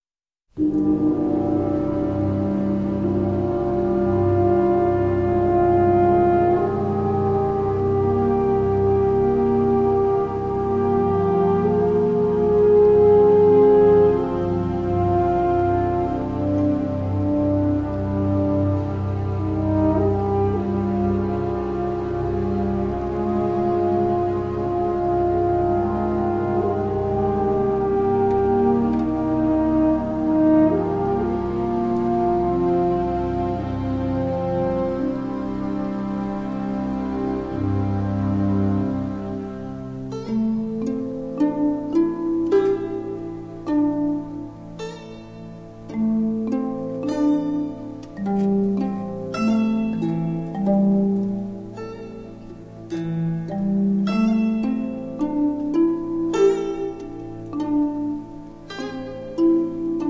CD 1 Original Motion Picture Soundtrack